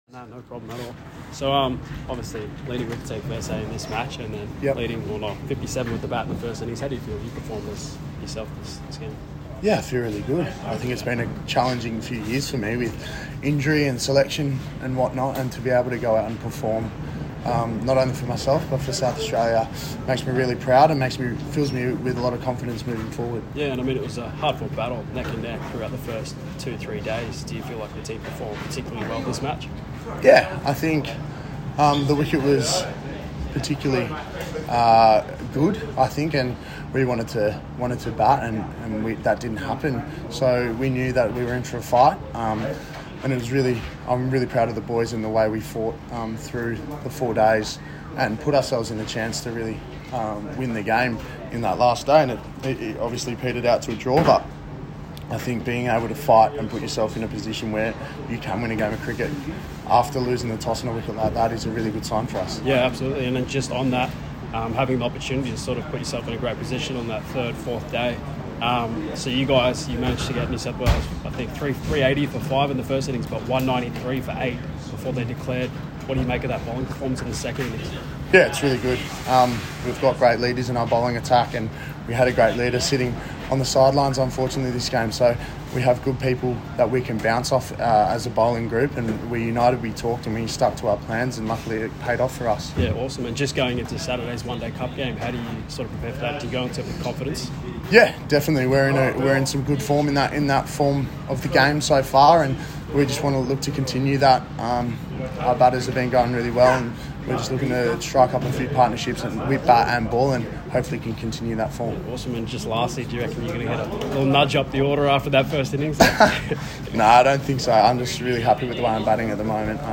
Wes Agar Day 4 post-match: SA vs NSW Sheffield Shield match in Wollongong